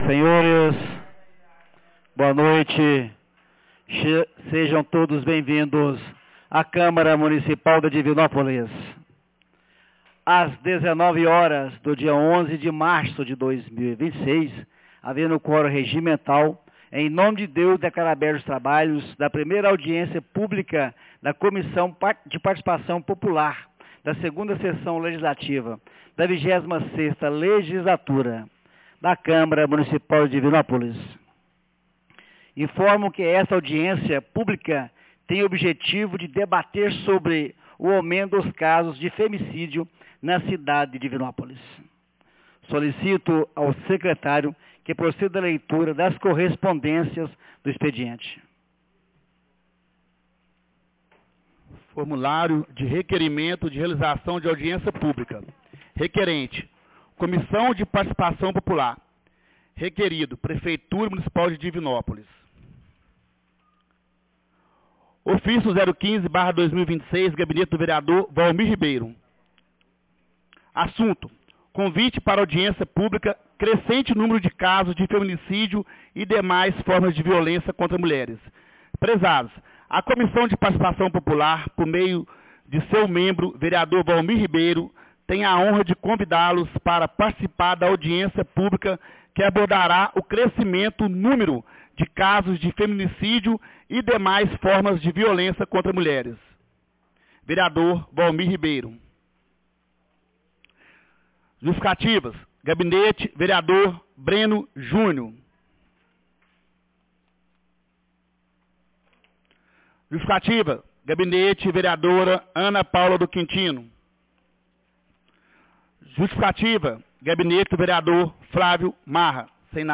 Audiencia Publica Comissão de participação popular 12 de março de 2026